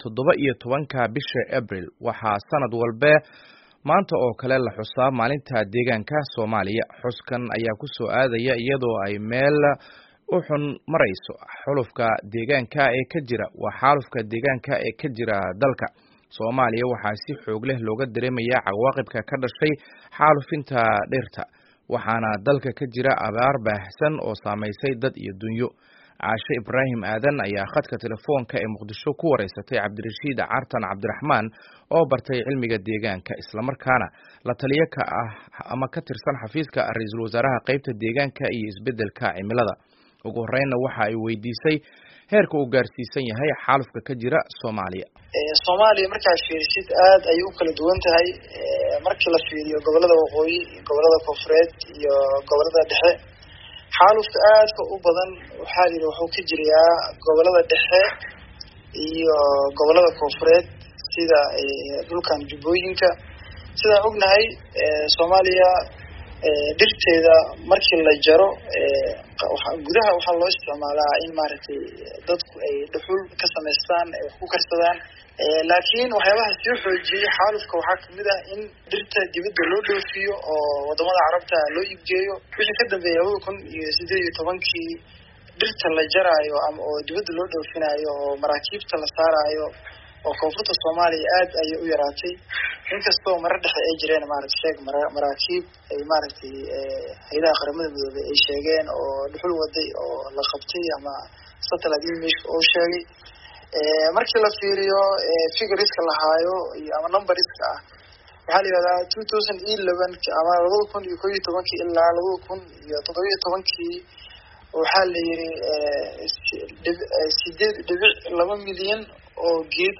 Waraysiga Xaaladda Deegaanka ee Soomaaliya